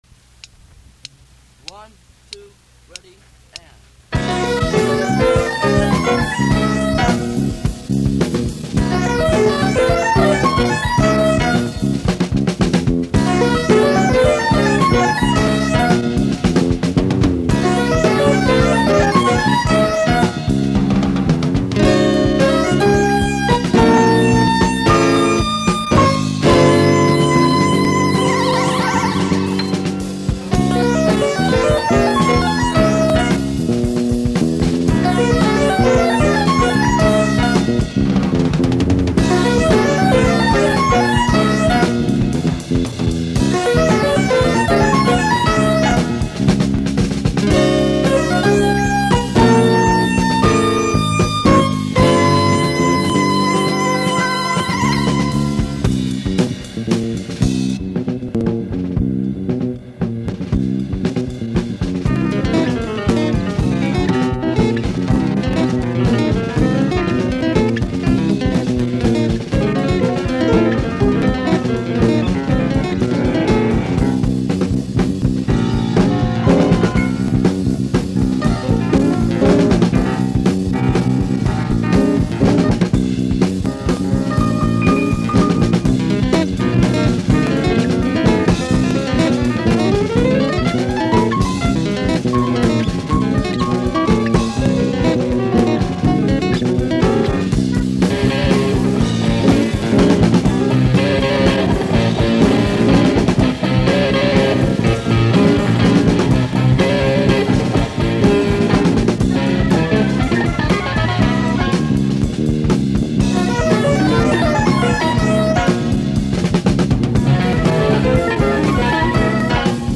drums
guitar
keyboards